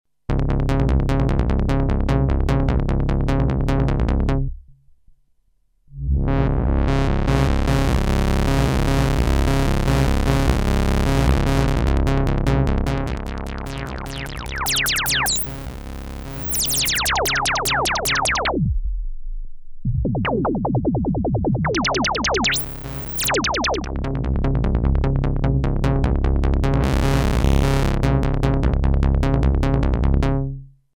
Elka Synthex
Bouncy bass